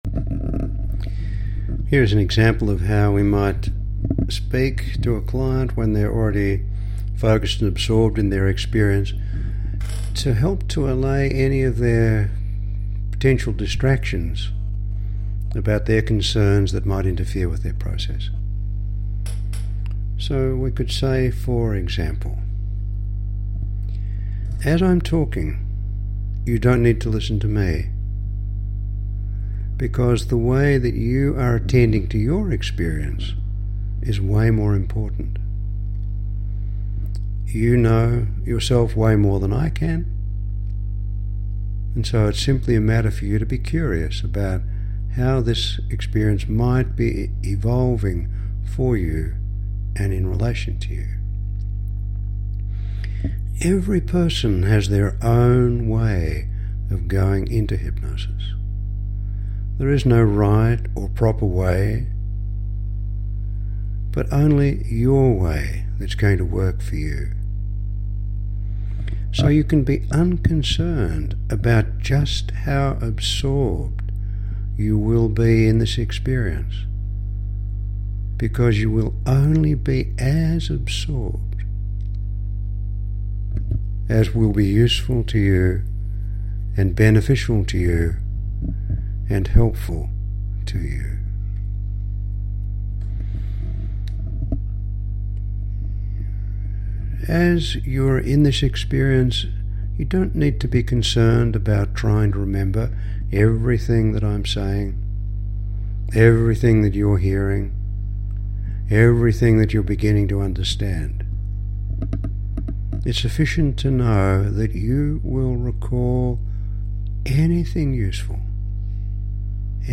It's very soothing, and it gave me a sense of safety.